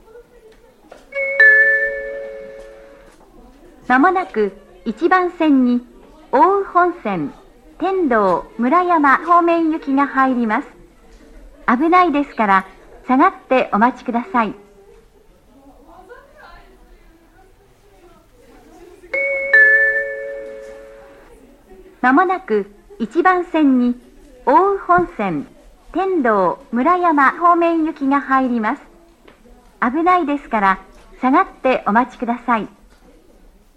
山形駅と比べると「奥羽本線、山形方面行き」と言った詳しい案内をしてくれる、親切な自動放送です。
アナウンスは上りが男声、下りが女性となっています。
下り接近放送(天童・村山方面)